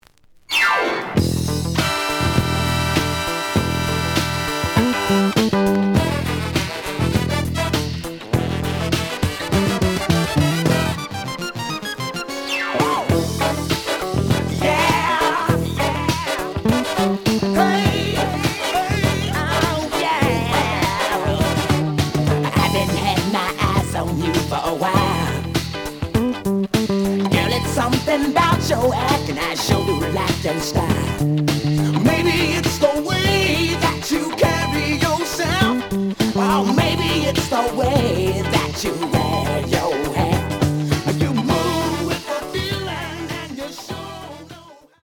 The audio sample is recorded from the actual item.
●Genre: Funk, 70's Funk
●Record Grading: VG~VG+ (傷はあるが、プレイはおおむね良好。Plays good.)